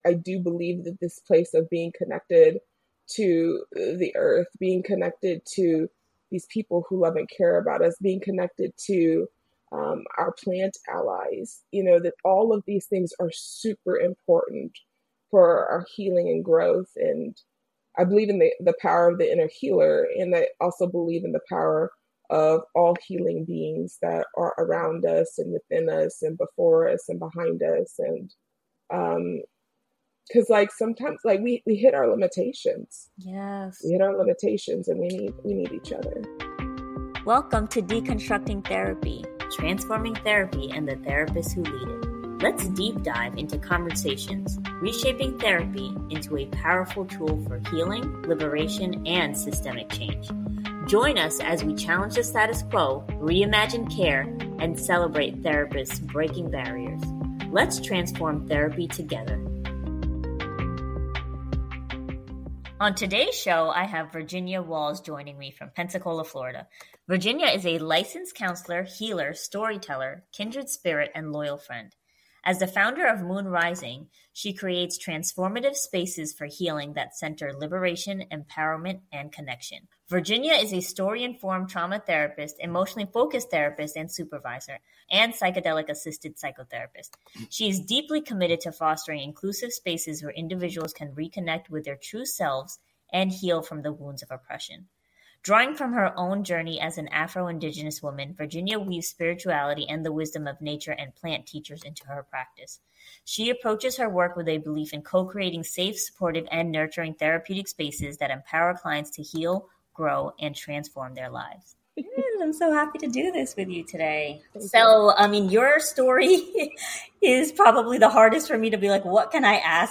I recently returned to this conversation and found myself hearing it differently.